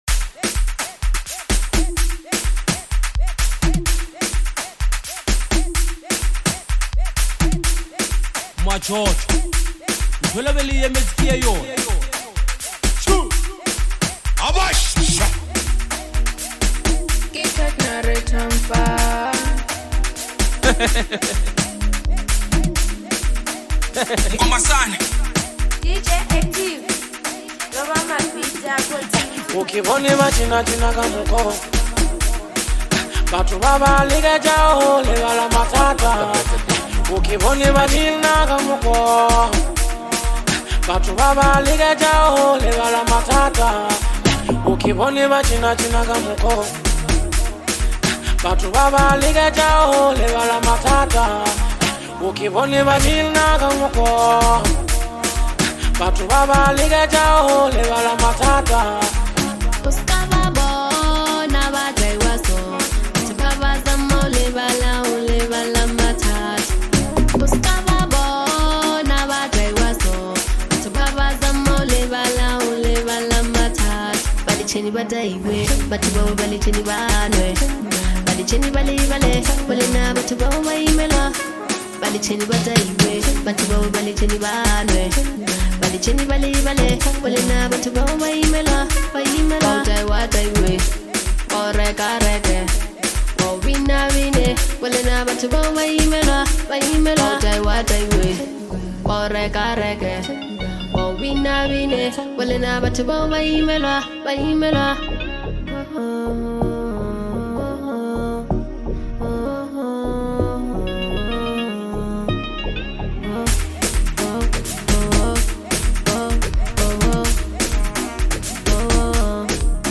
Powerful Vocals